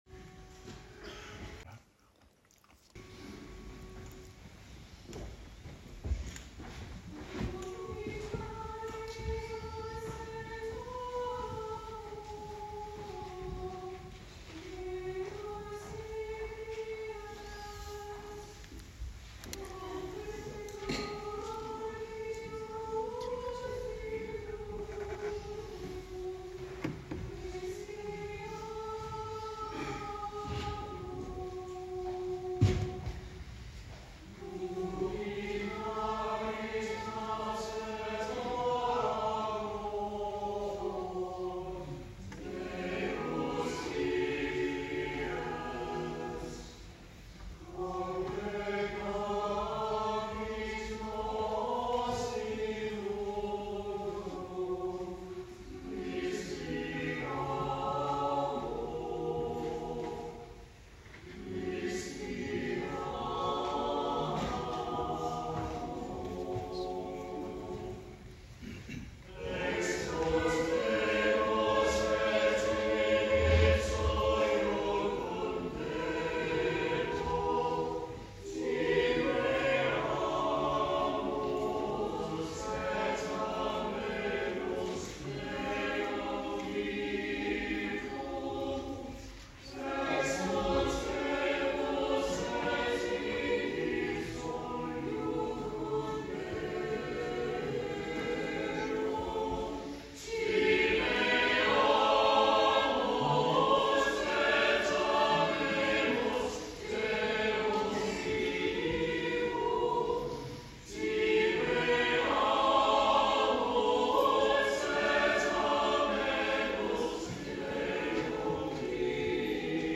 Anthem at Parish Eucharist